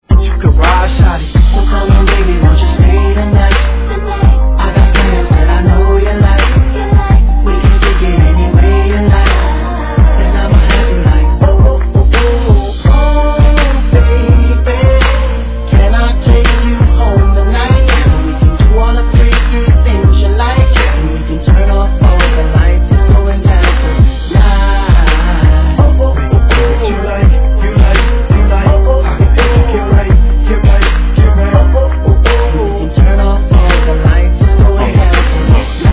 Tag       HIP HOP HIP HOP